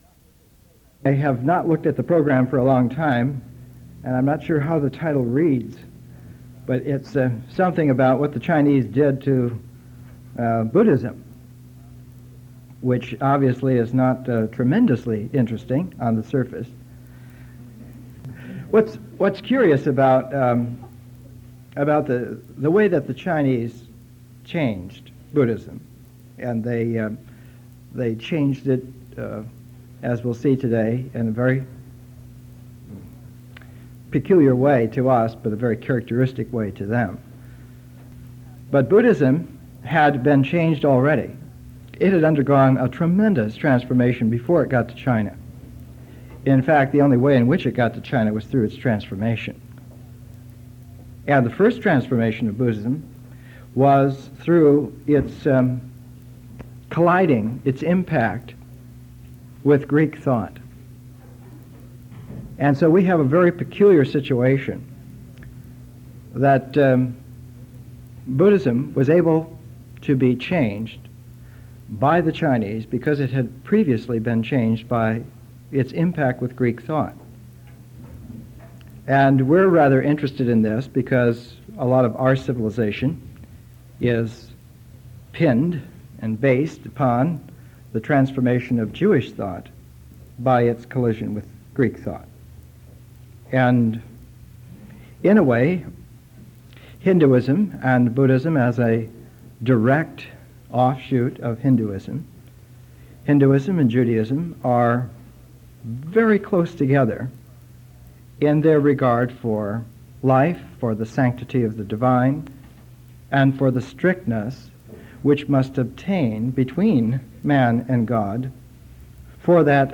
Presentations are 1-1.5 hours: an initial session of 30-45 minutes, an intermission for discussion or contemplation, and a second 30-45 minute session.